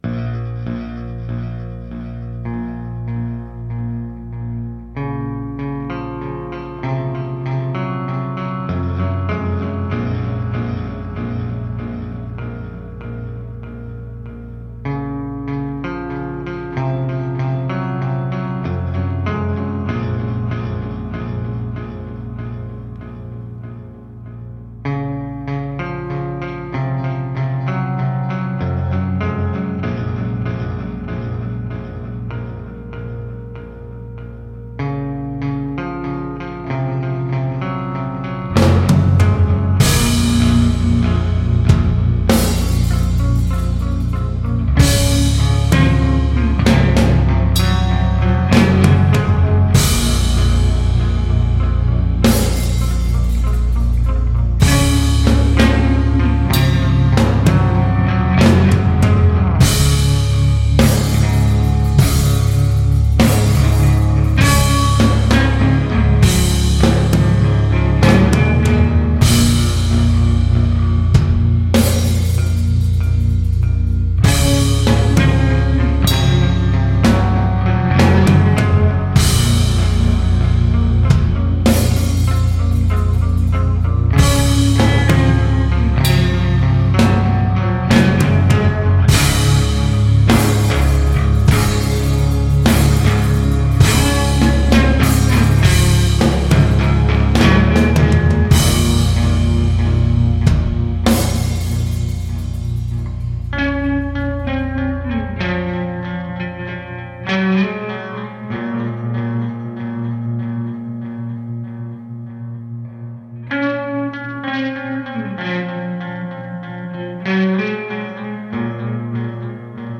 a doom band from Linköping.